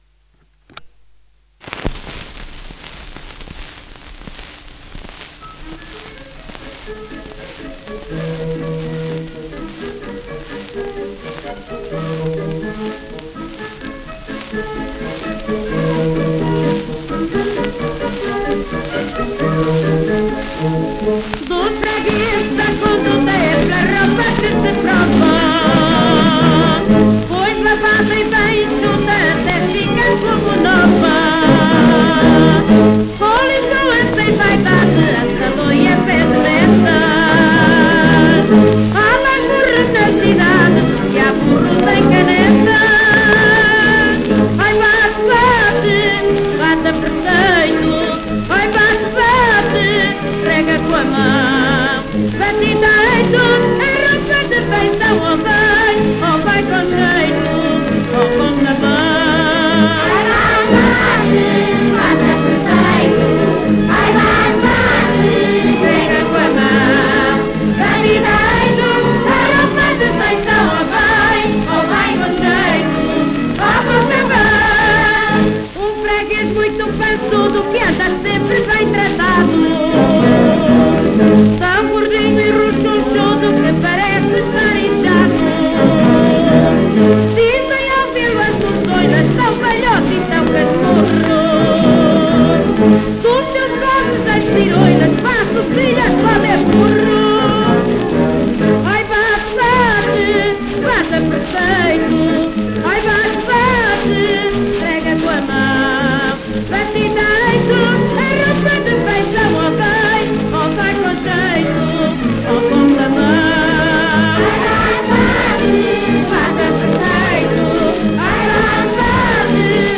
inetmd-fcsh-ifpxx-mntd-audio-lavadeiras_de_canecas_as_cancao-1302.mp3